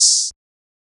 TM88 - OPEN HAT (4).wav